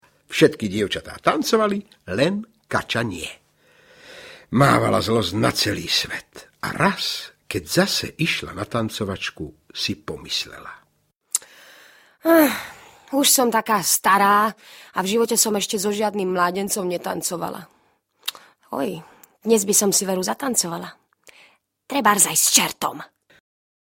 Najkrajšie rozprávky 7 audiokniha
Obsahuje rozprávky Čert a Kača, O Smolíčkovi a O kohútkovi a sliepočke, v podaní výborného Mariána Labudu.
Ukázka z knihy